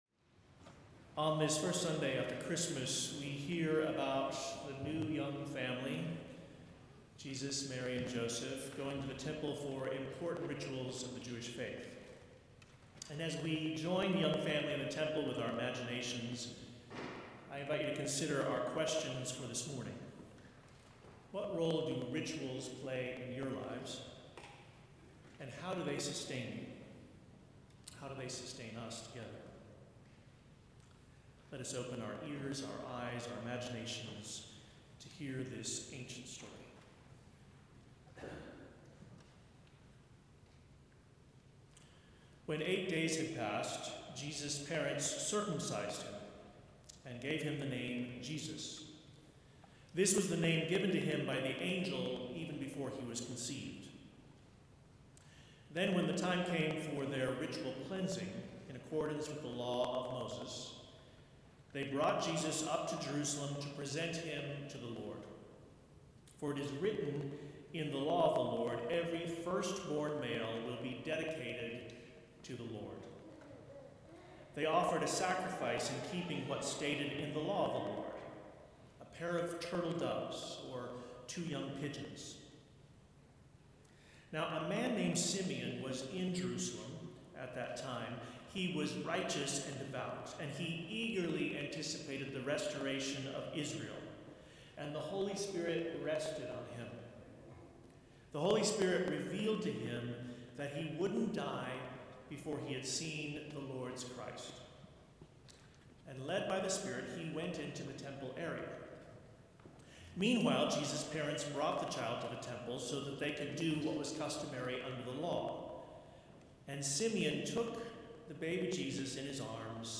About Us Sermons How Does a Weary World Rejoice?